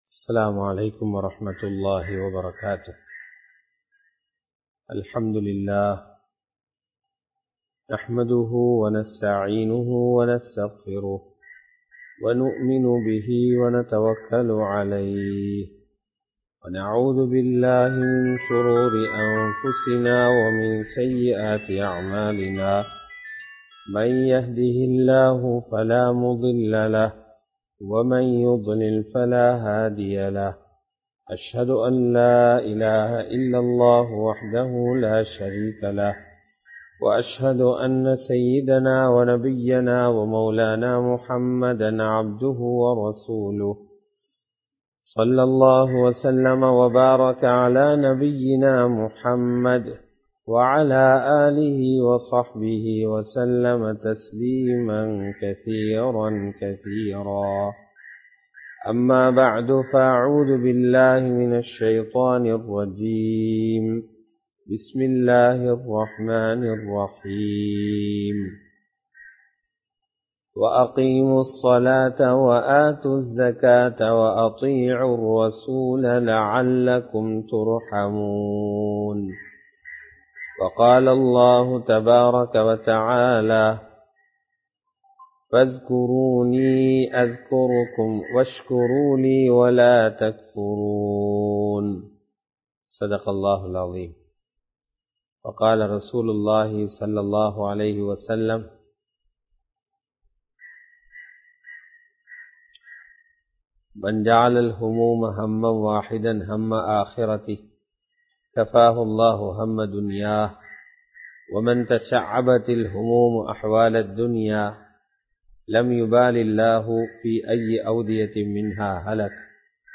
உறுதியான ஈமான் | Audio Bayans | All Ceylon Muslim Youth Community | Addalaichenai